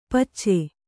♪ pacce